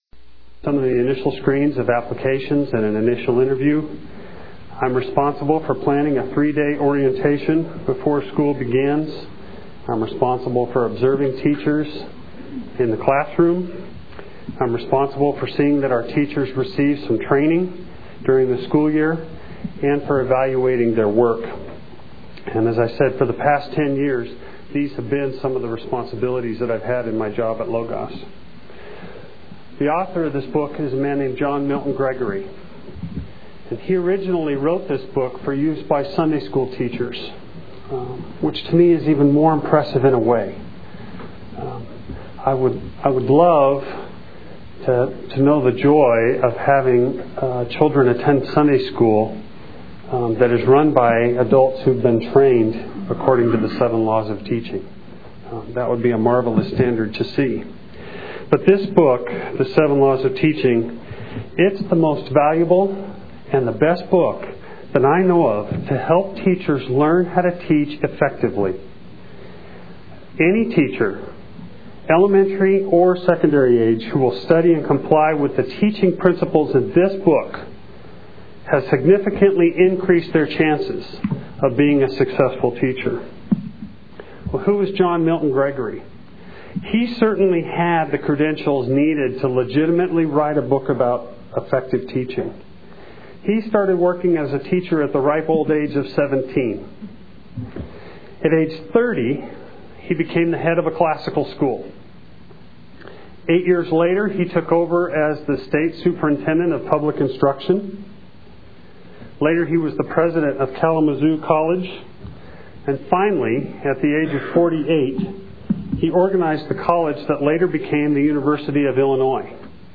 2001 Workshop Talk | 0:53:29 | All Grade Levels, General Classroom
The Association of Classical & Christian Schools presents Repairing the Ruins, the ACCS annual conference, copyright ACCS.